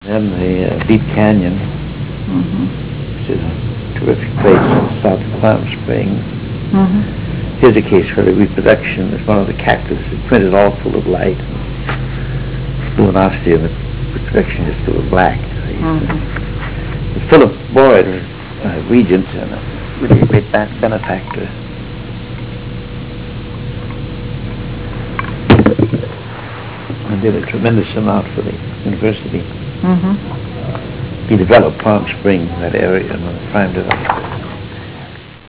285Kb Ulaw Soundfile Hear Ansel Adams discuss this photo: [285Kb Ulaw Soundfile]